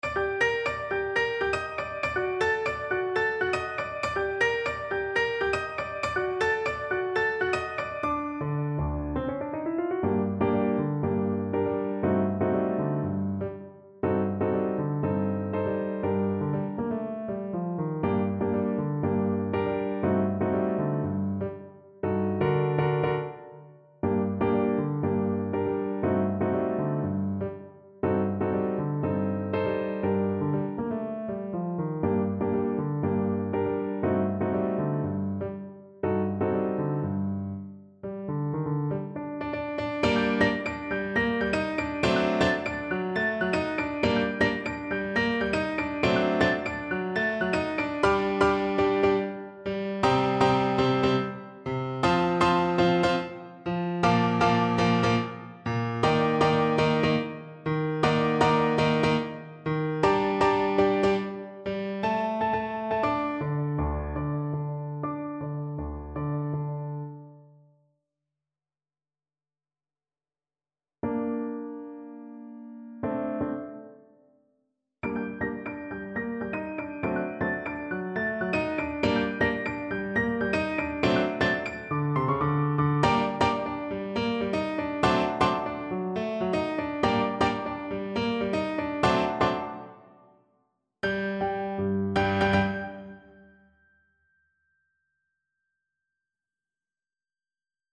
A firey salsa-inspired piece.
Energico =120
Jazz (View more Jazz Trumpet Music)